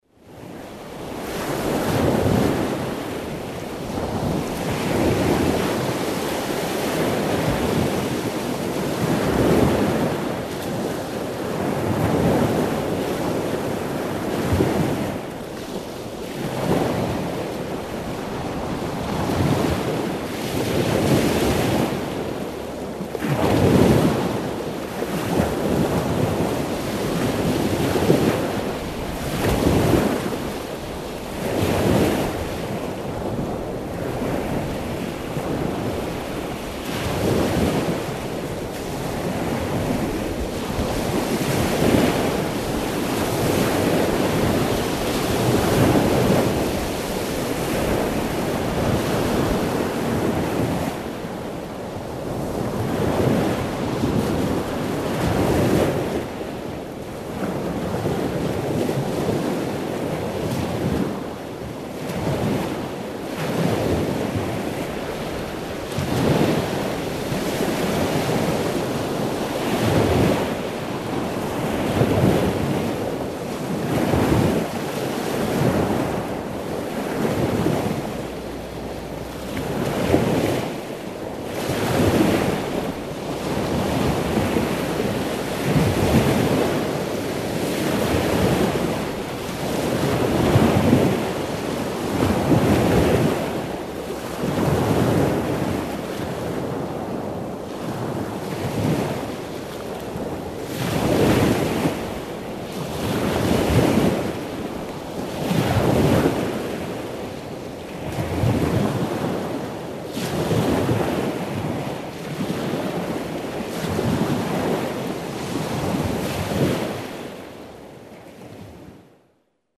Звуки моря, океана
Шум океана (мощные звуки волн)